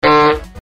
Trumpet Sound Sound Button: Unblocked Meme Soundboard